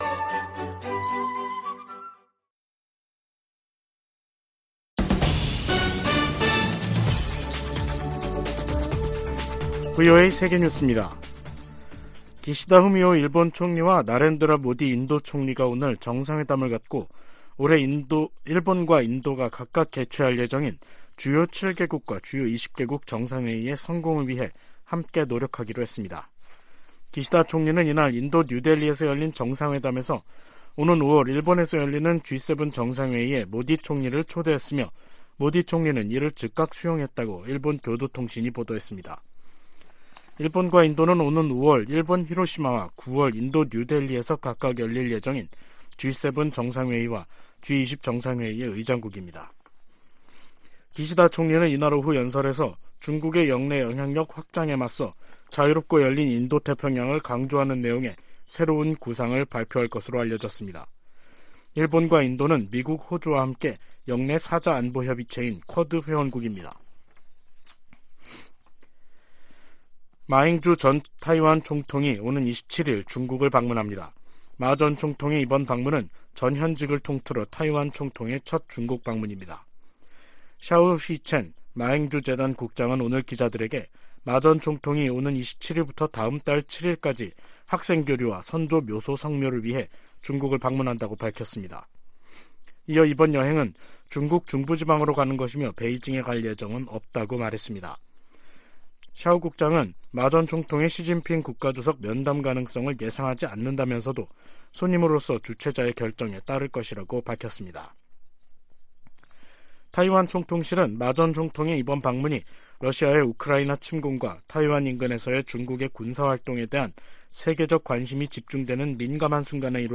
VOA 한국어 간판 뉴스 프로그램 '뉴스 투데이', 2023년 3월 20일 2부 방송입니다. 북한은 김정은 국무위원장이 참관한 가운데 한국의 주요 대상을 겨냥한 핵 반격 전술훈련을 했다며 또 다시 핵 공격 위협을 가했습니다. 북한의 탄도미사일 발사 등 도발적 행동이 역내 불안정을 초래하고 있다고 미국 인도태평양사령관이 말했습니다. 유엔 안보리 회의에서 미국이 북한 인권과 대량상살무기 문제가 직결돼 있다며 이를 함께 제기해야 한다고 강조했습니다.